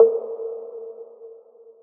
11. lose you snare.wav